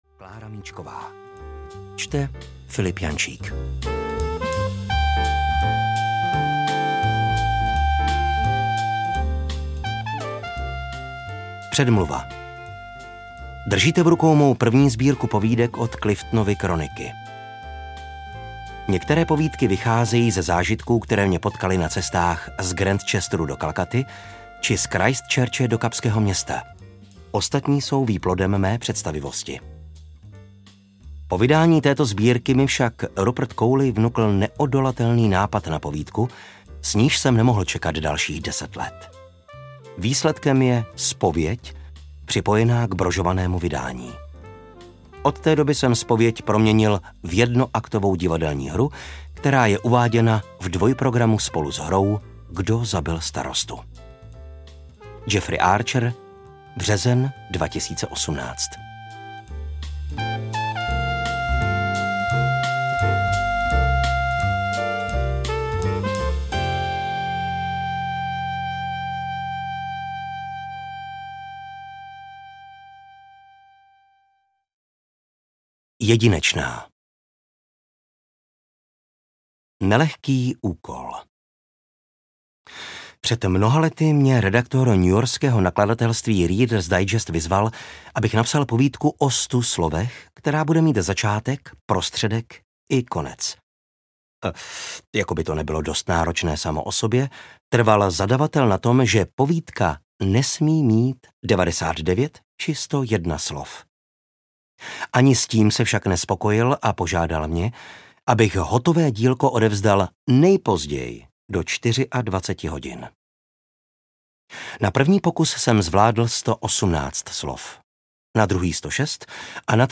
(Ne)povídej audiokniha
Ukázka z knihy